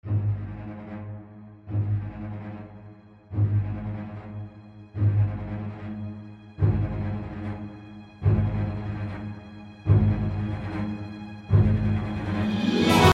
please try to listen closely to the first bar. it sounds perfect
in the second bar, although its the same midi information, the last note sounds somewhat like a double note, in the third bawr its the one before the last...
repetitions_110bpm.mp3